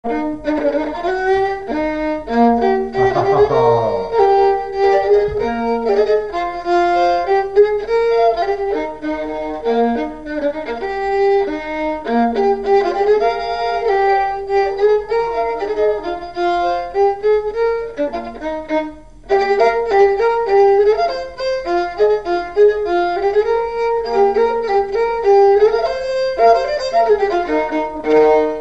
Polka berline
Résumé instrumental
gestuel : danse
Catégorie Pièce musicale inédite